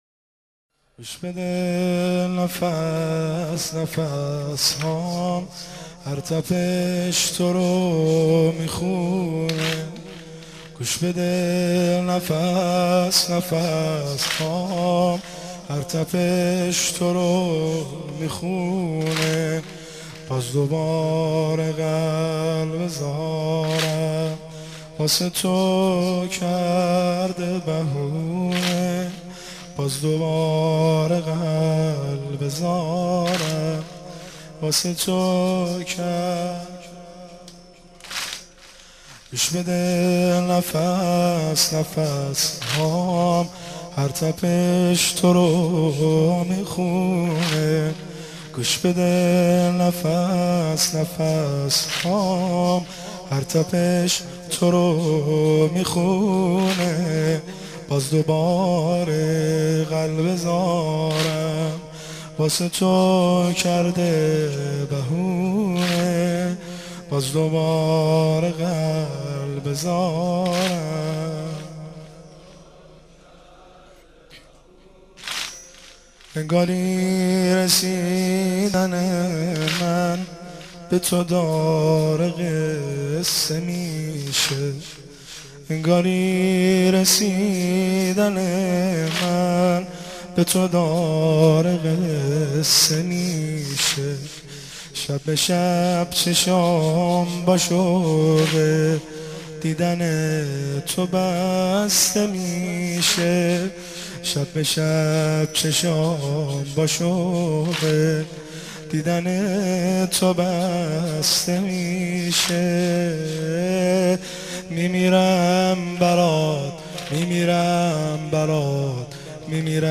مداح
مناسبت : ولادت حضرت مهدی عج‌الله تعالی‌فرج‌الشریف